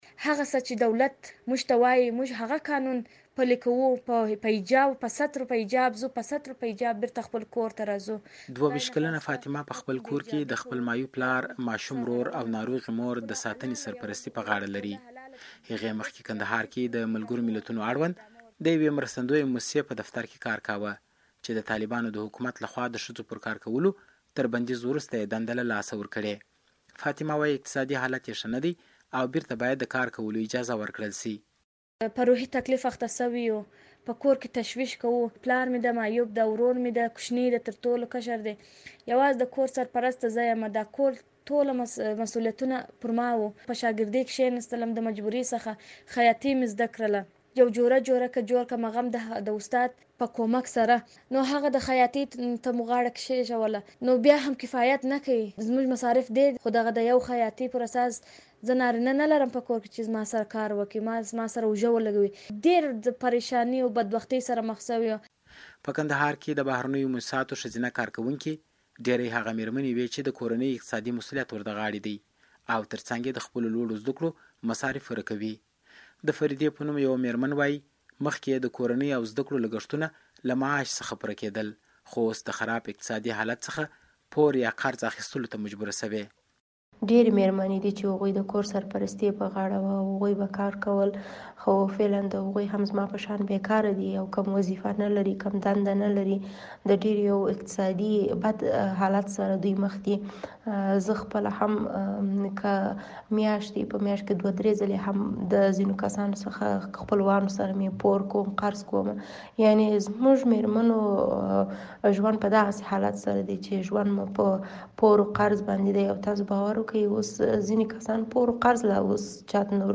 د کندهار راپور